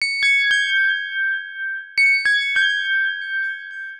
Pensive (Chimes) 120BPM.wav